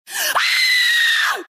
Звуки криков людей
Женщина громко крикнула